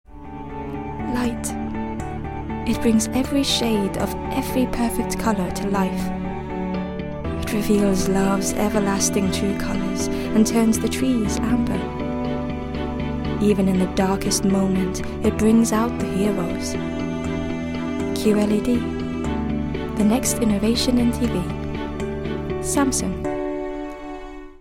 Commercial Reel - Accented English
I'm a highly experienced Scandinavian voice artist with a fully equipped home studio in London.